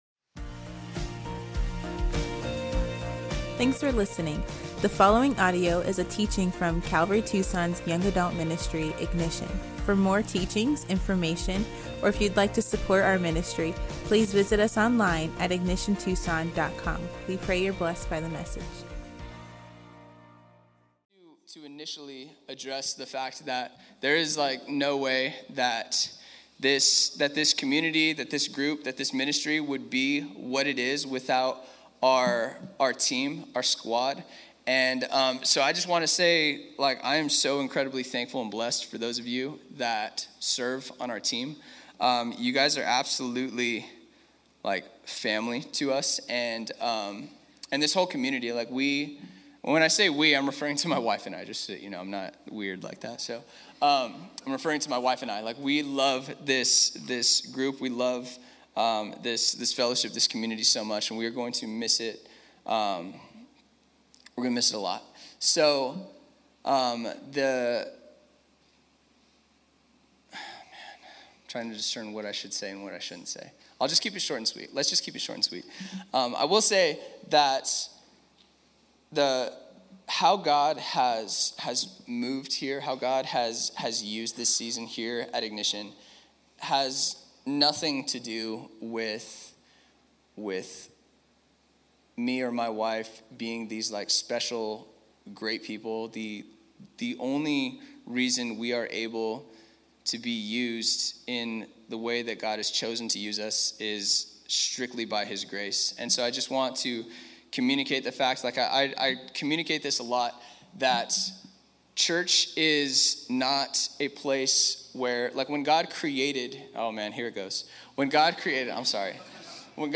at Ignition Young Adults on August 15, 2025.